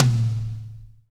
-DRY TOM 4-R.wav